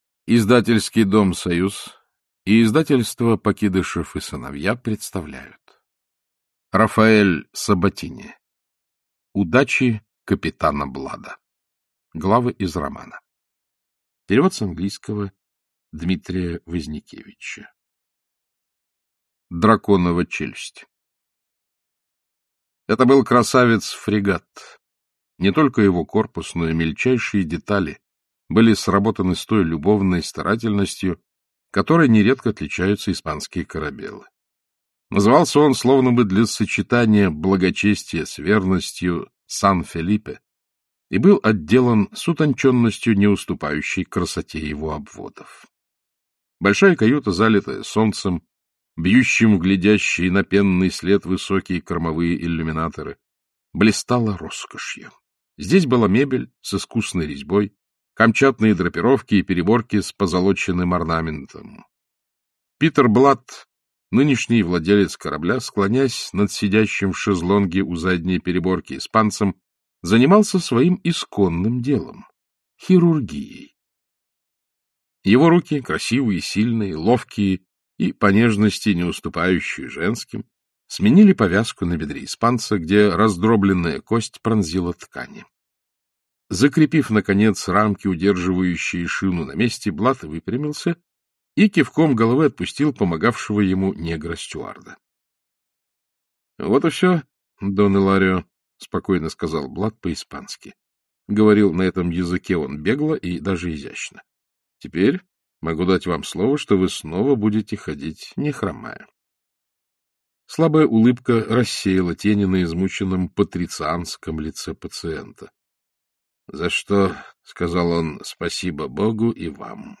Аудиокнига Удачи капитана Блада | Библиотека аудиокниг